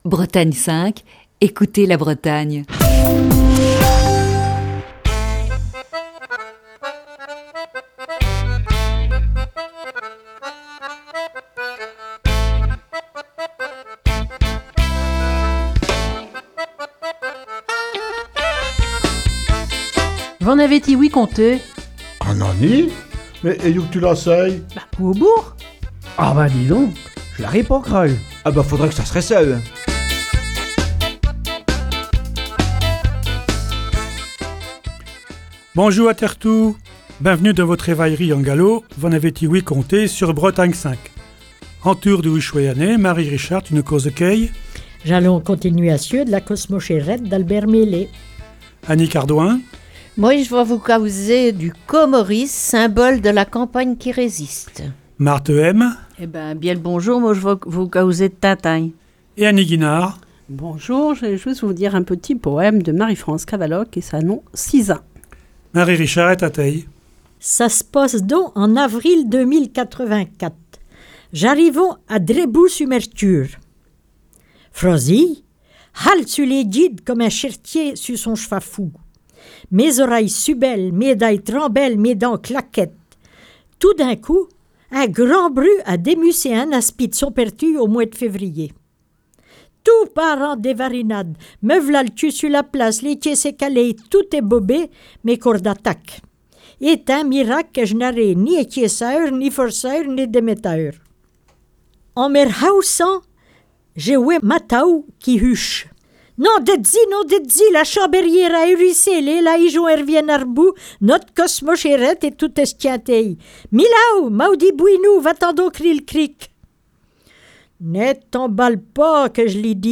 L'équipe de V'en avez-ti ouï conté ? s'accorde quelques semaines de vacances, mais reste présente sur les ondes de Bretagne 5, grâce aux rediffusions d'été !
(Émission diffusée le 3 novembre 2019).